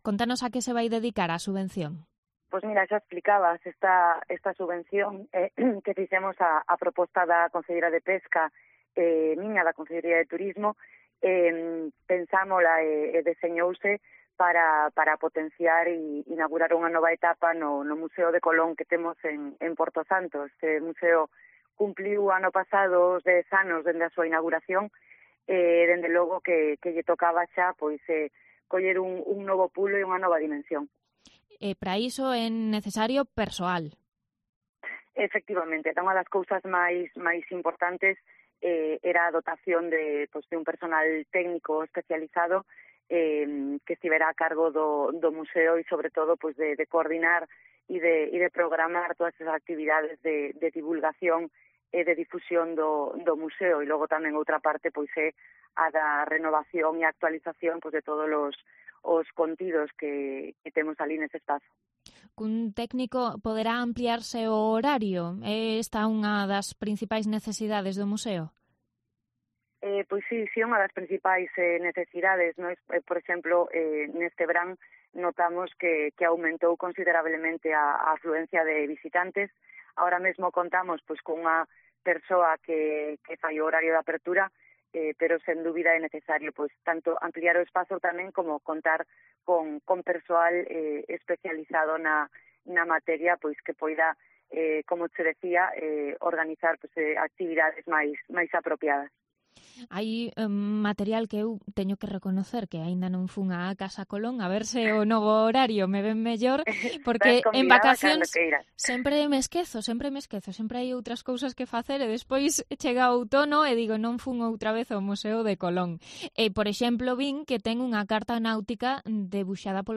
Entrevista a la concejala de Turismo de Poio, Silvia Díaz